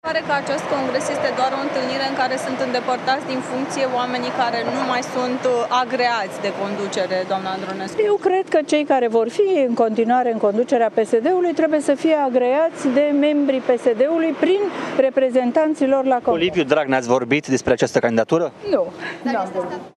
Ecaterina Andronescu le-a spus jurnaliștilor adunați la sediul PSD…că nu s-a sfătuit cu Liviu Dragnea despre candidatură: